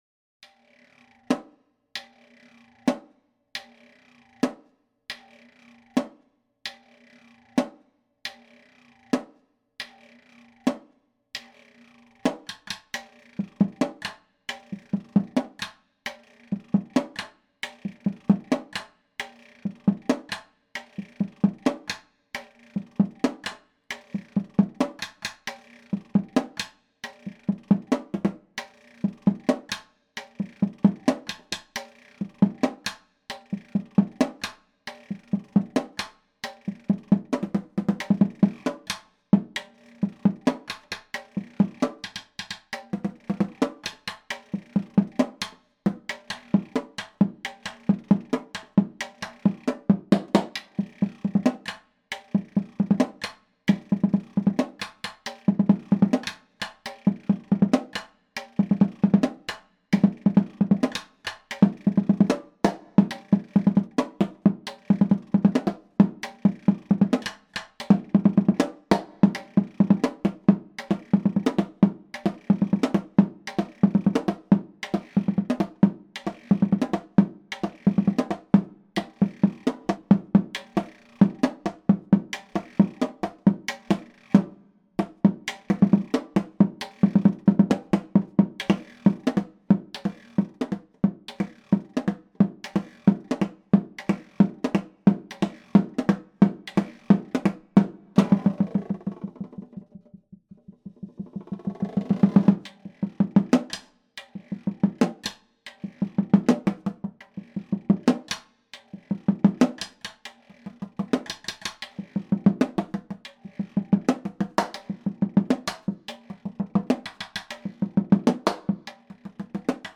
Voicing: Snare Solo